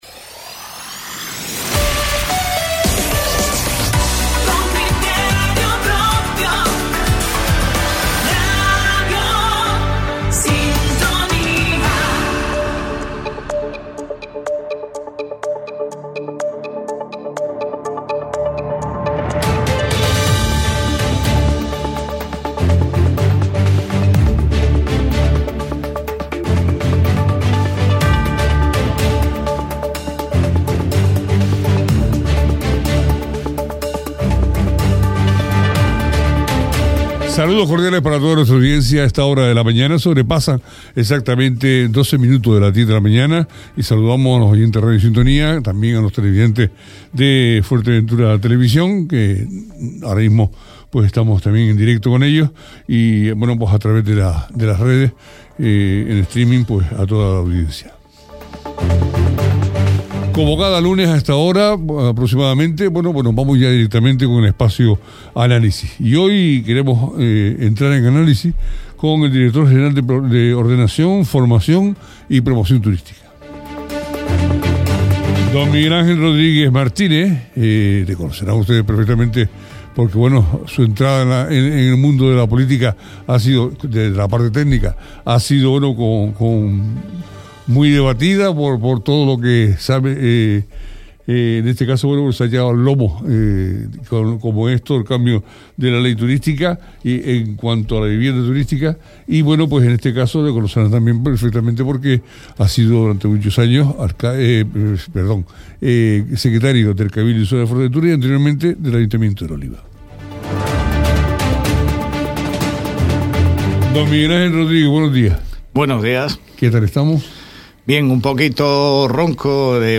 Miguel Ángel Rodríguez, director general de Ordenación, Formación y Promoción Turística del Gobierno de Canarias ha sido el protagonista del espacio Análisis de Radio Sintonía